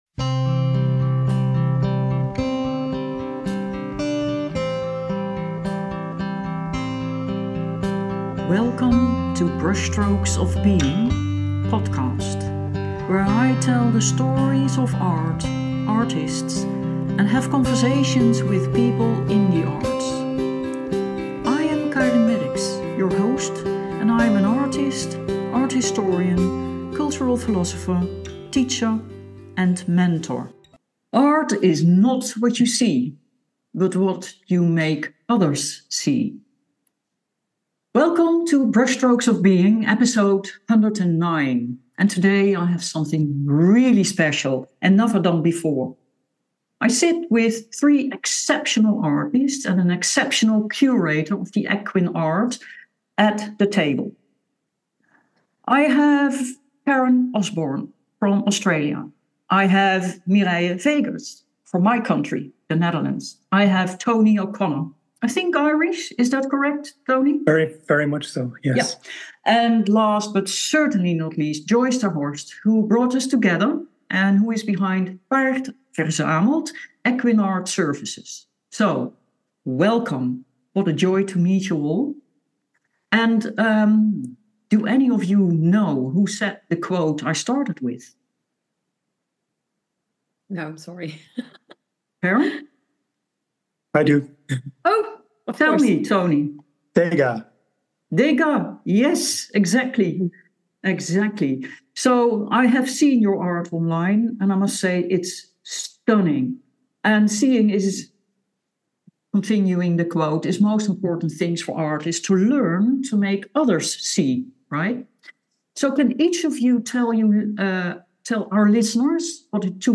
In this illuminating roundtable